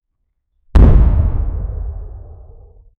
explosion-of-an-airplane--cugq6vt3.wav